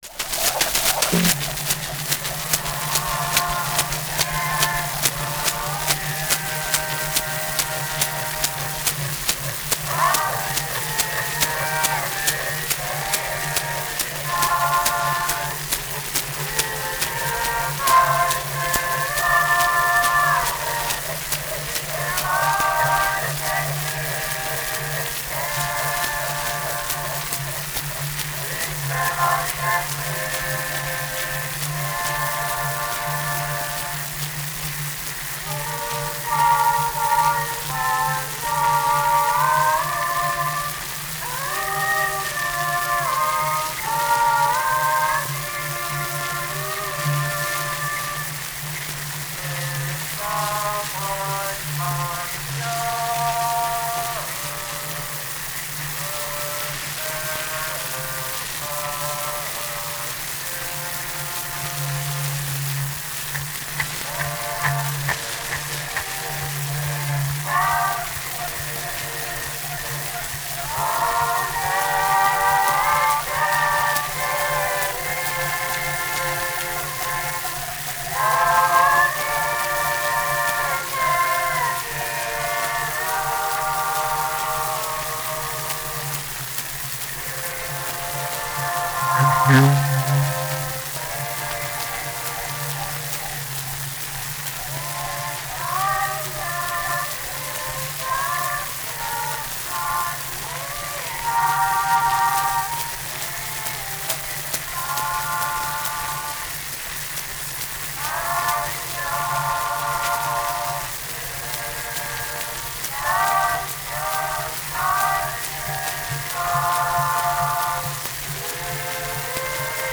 [Brown wax home recording of choral piece]. Performers: Vocals by unidentified choir. Release year: 1900 Cylinder 14262 Play 0:00 0:00 Mute Download Share: Twitter Facebook Notes: Brown wax concert cylinder.